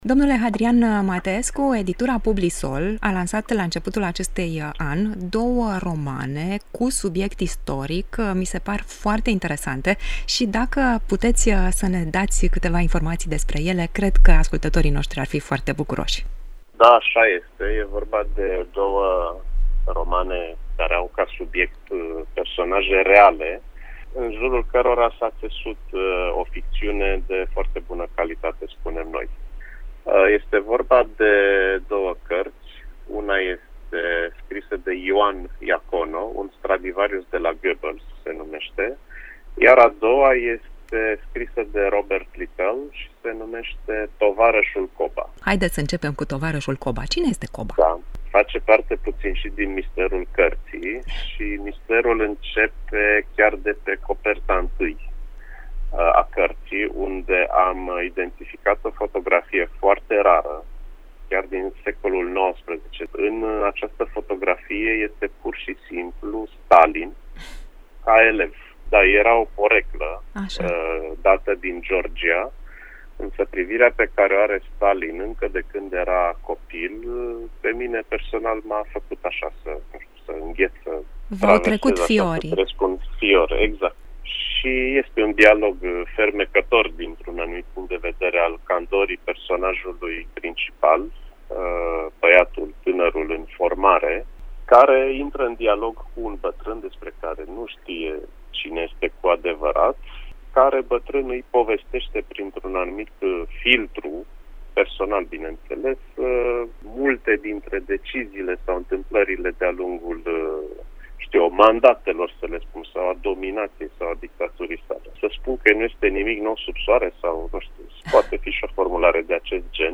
Ați ascultat un interviu